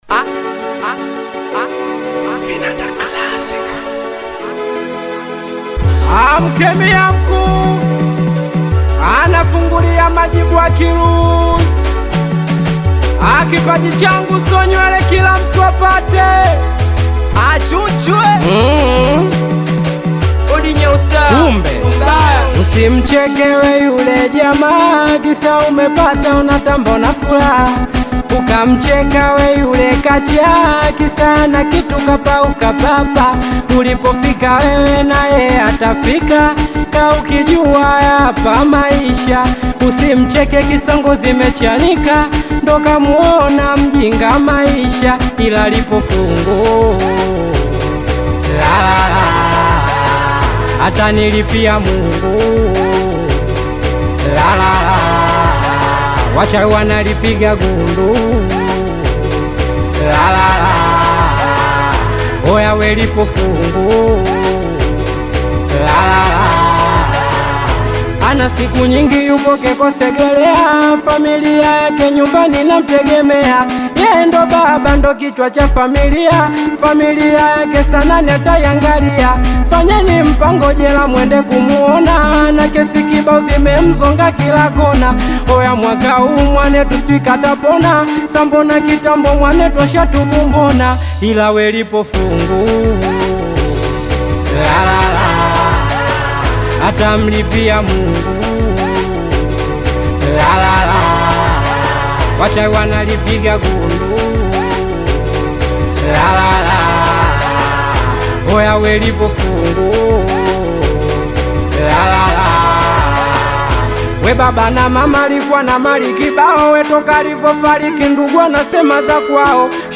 AudioSingeli
high-energy Singeli single
energetic East African dance music